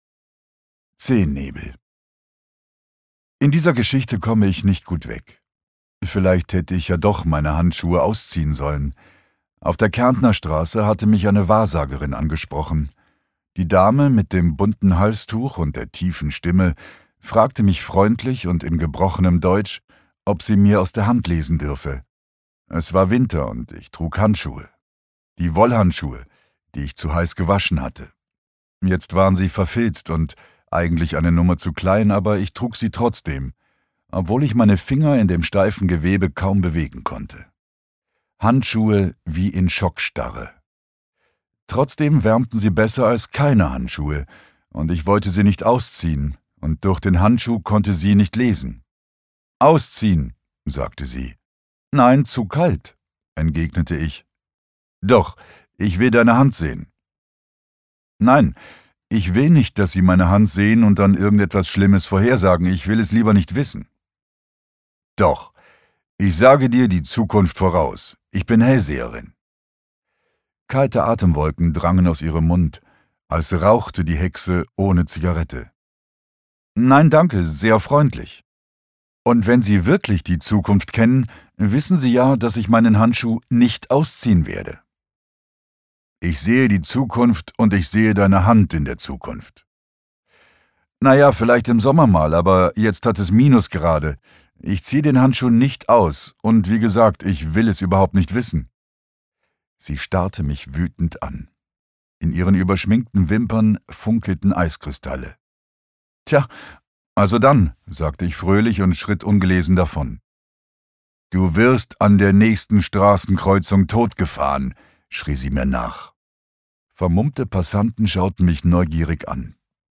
Hörprobe Maksym Ungekürzt. Dirk Stermann (Autor) Dirk Stermann (Sprecher) Audio-CD 2022 | 1.